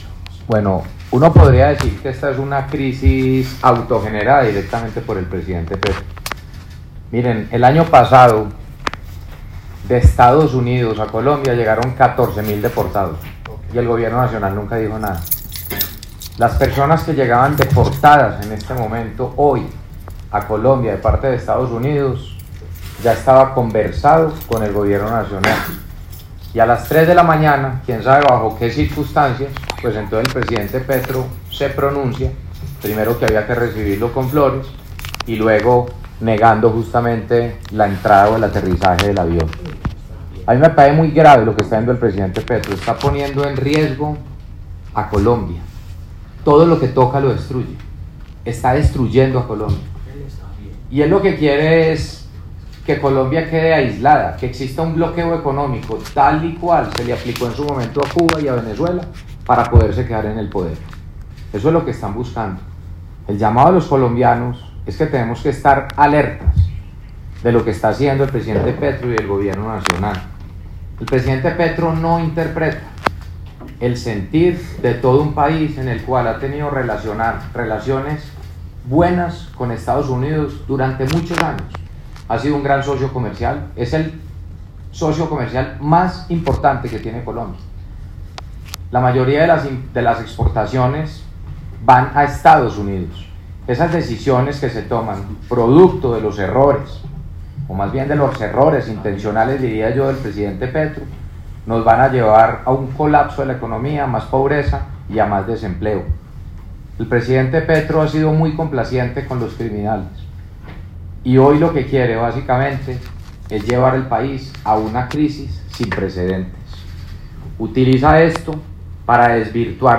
“Petro no nos representa”: alcalde de Medellín, Federico Gutiérrez